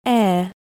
ĕ
E-edge-phoneme-AI.mp3